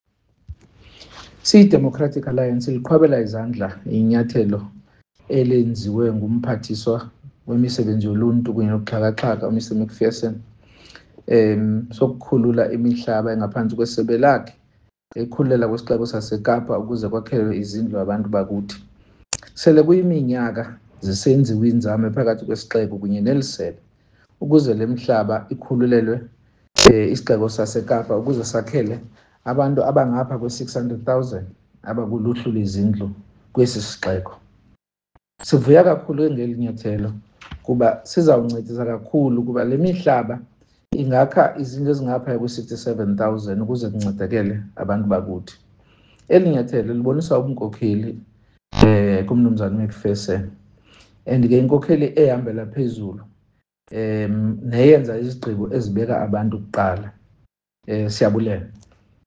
isiXhosa soundbite by Bonginkosi Madikizela MP.